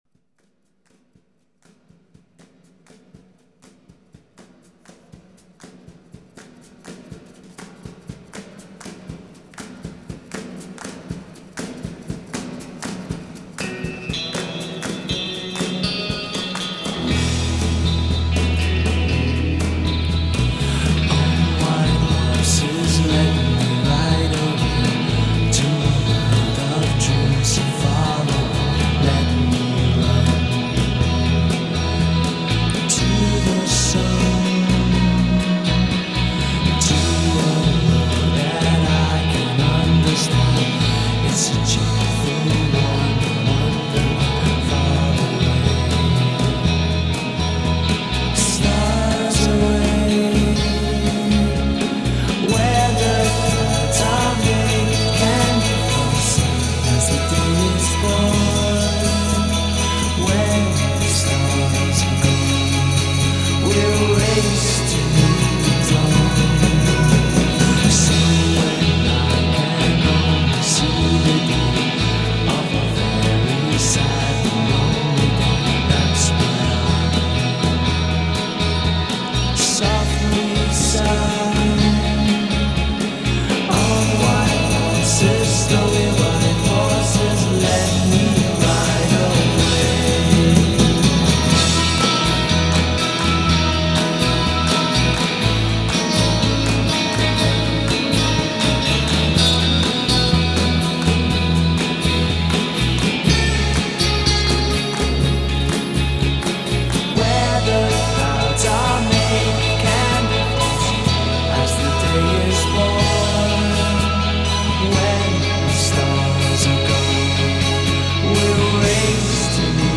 dreamy ballads with an effortless flow